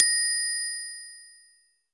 Glocken.mp3